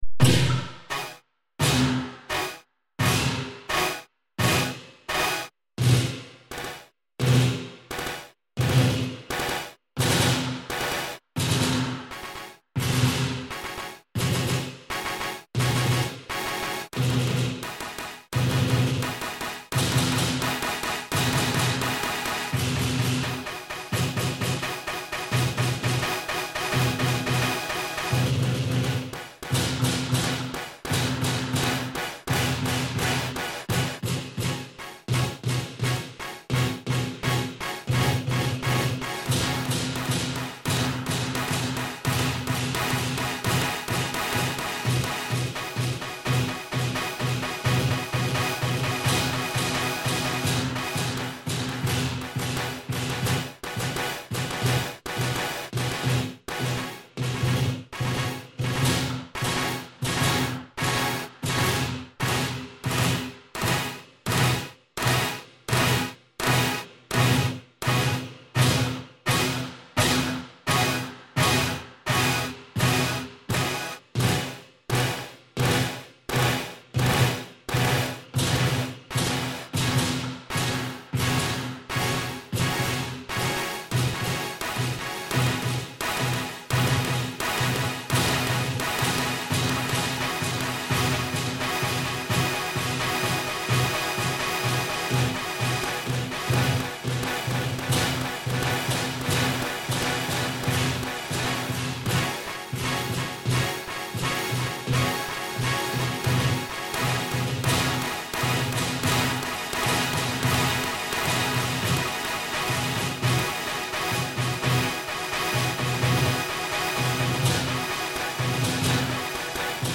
Greenwich New Year celebration reimagined